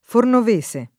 fornovese [ fornov %S e ] etn.